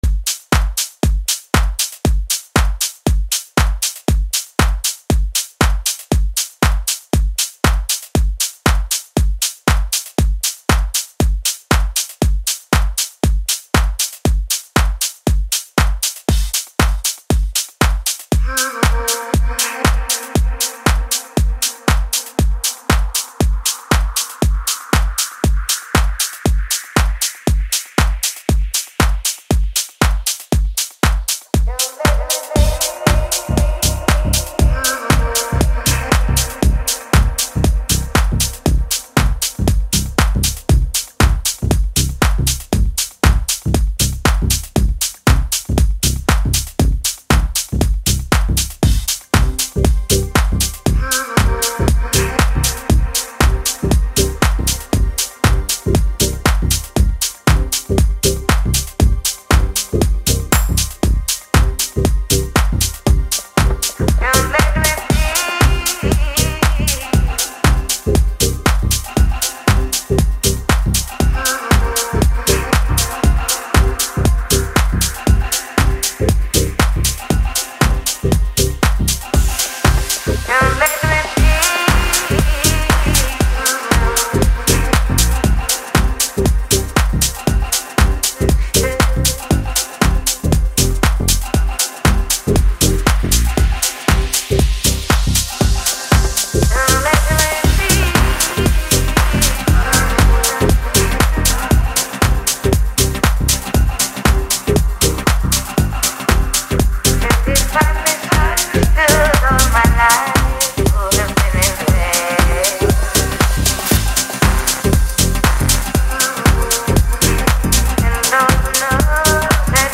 Home » Amapiano » Gqom